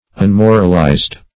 Unmoralized \Un*mor"al*ized\, a.
unmoralized.mp3